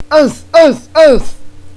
So in my vast amount free time, i have recorded myself saying a number of things retardely.